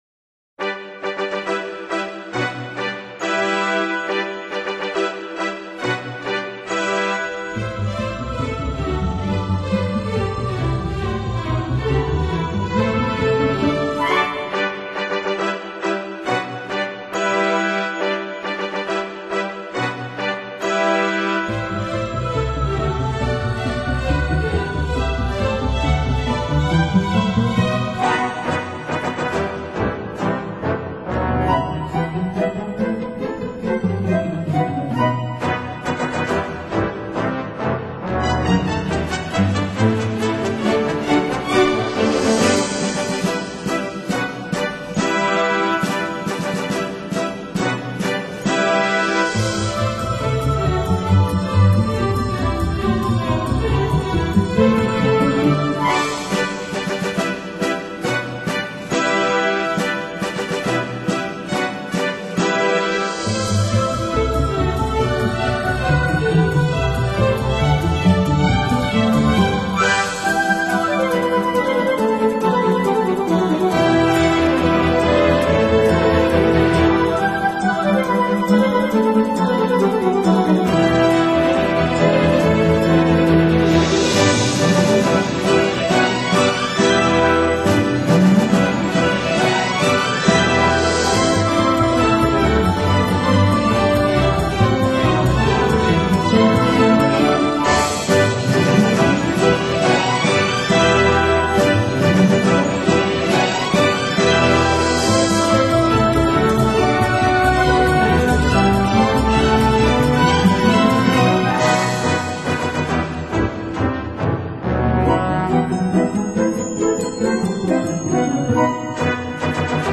音乐风格：Contemporary Instrumental / Christmas / Holiday